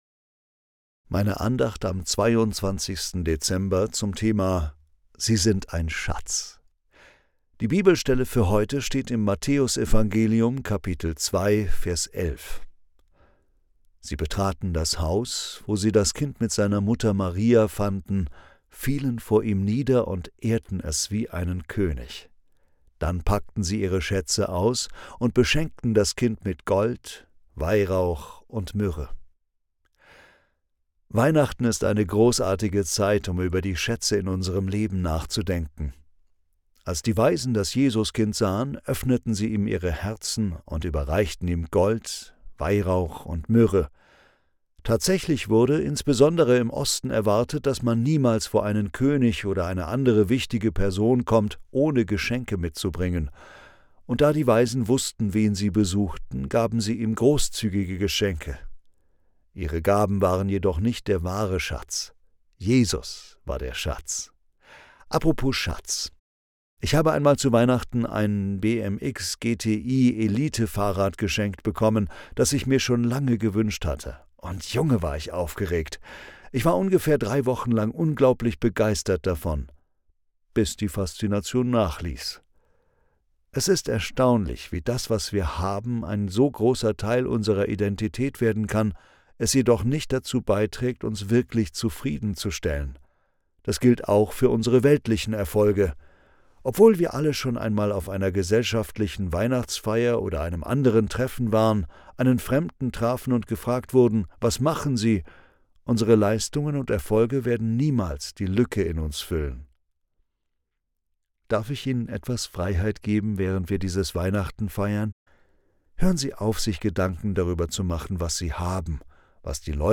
Andacht zum 22. Dezember